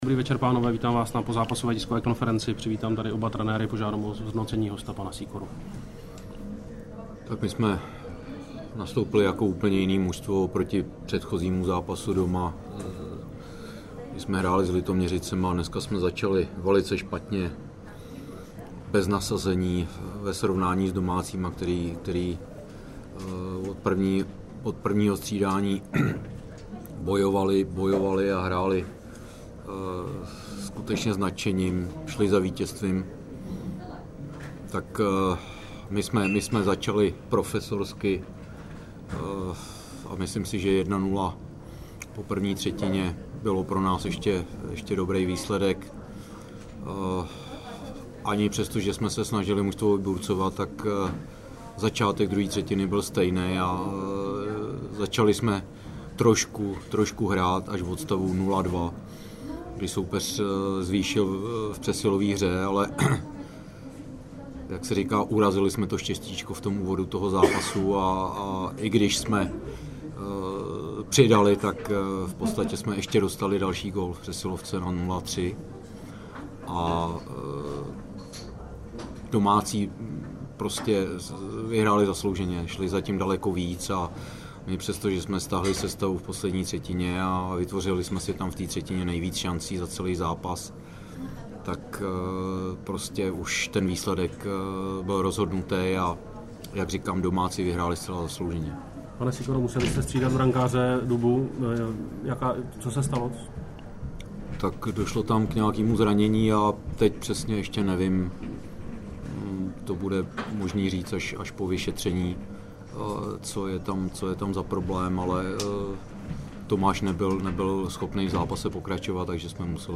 Tiskovka po utkání Třebíč - Chomutov 4:1